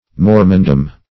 Search Result for " mormondom" : The Collaborative International Dictionary of English v.0.48: Mormondom \Mor"mon*dom\, n. The country inhabited by the Mormons; the Mormon people.